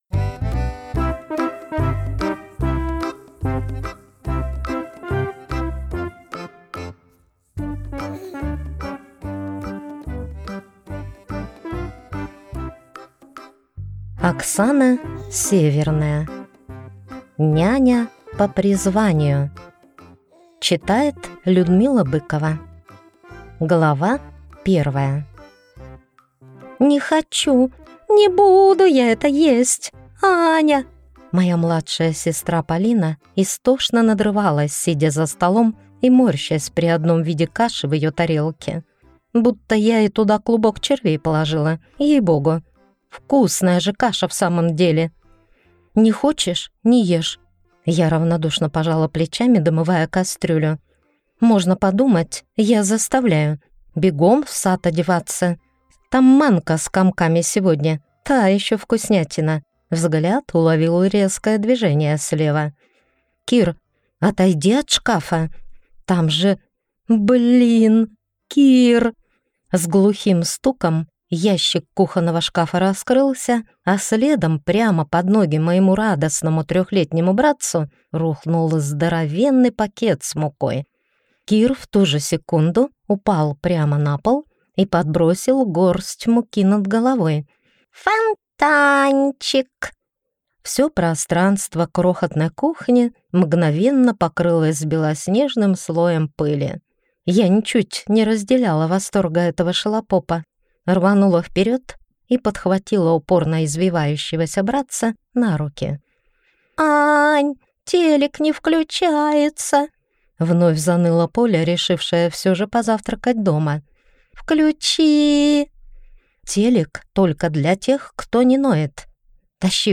Аудиокнига Няня по призванию | Библиотека аудиокниг